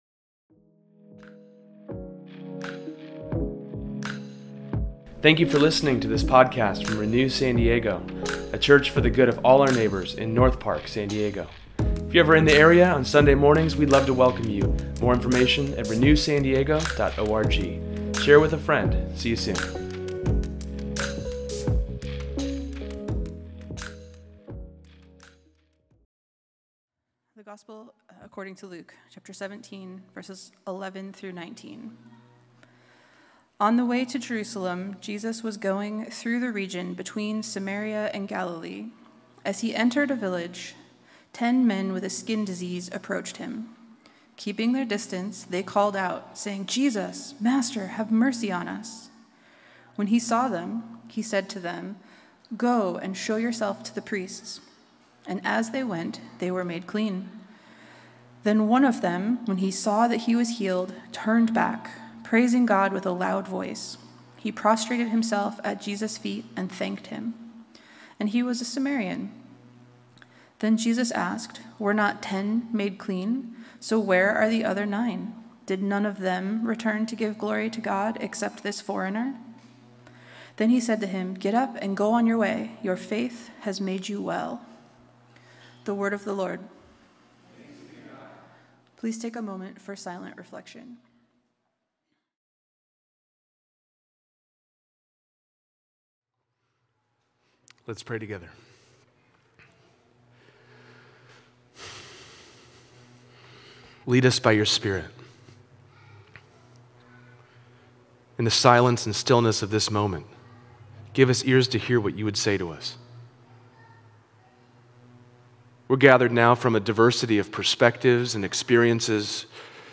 In today’s sermon we learn about Jesus’s healing, in the physical in his time, but how he is making all things new for us.